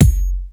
T1KIK004.WAV